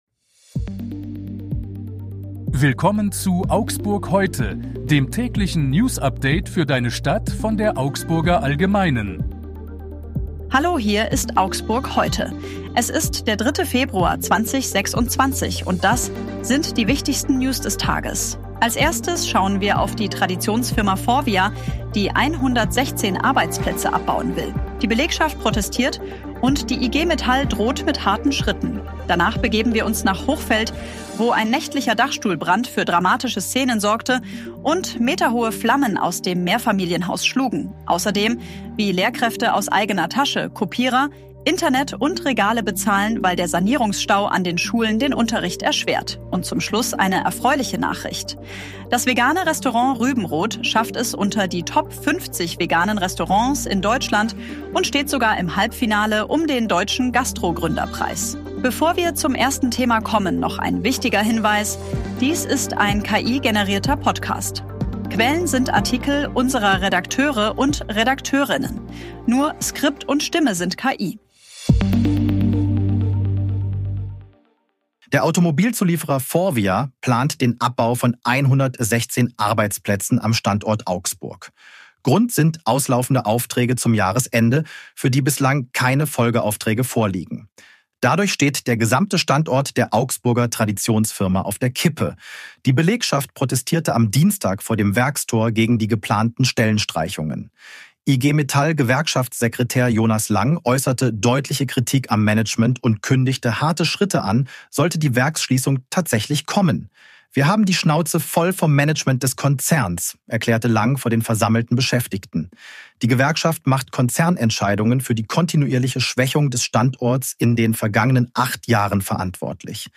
Hier ist das tägliche Newsupdate für deine Stadt.
Nur Skript und Stimme sind KI.